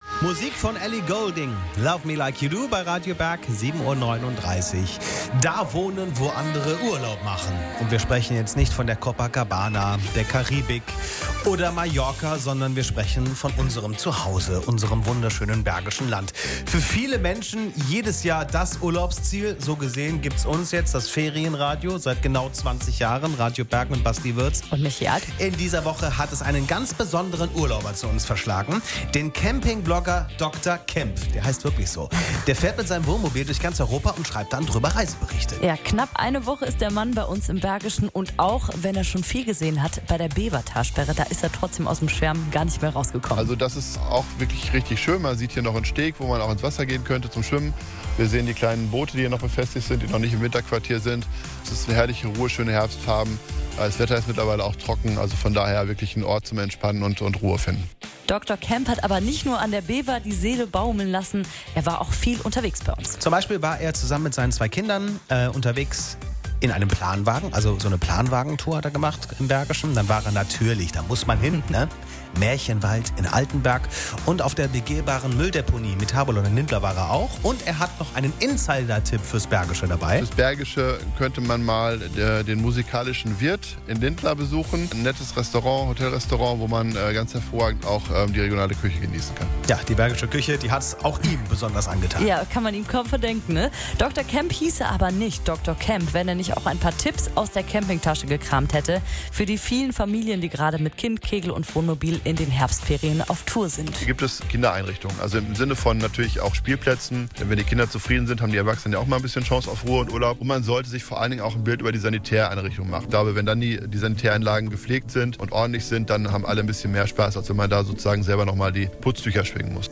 Übrigens hat RADIO BERG ein Interview mit mir gemacht und in einen ziemlich genialen Beitrag gepackt!